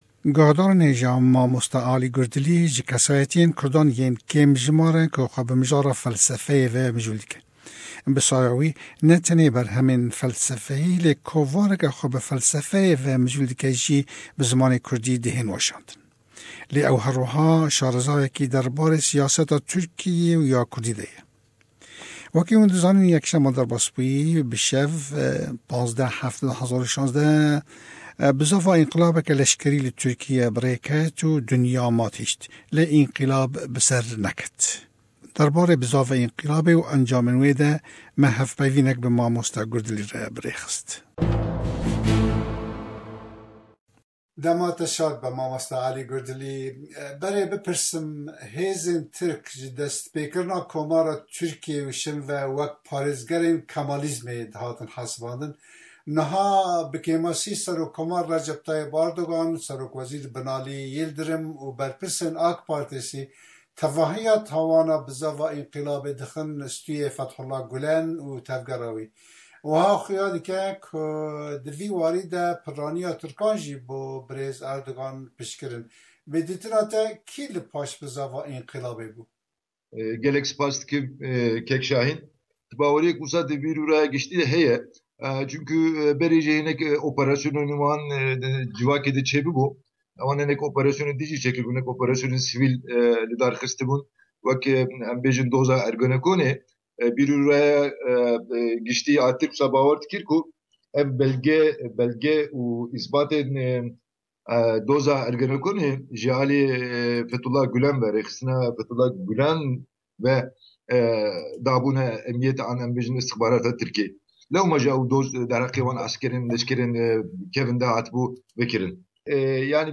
Interview about the situation in Turkey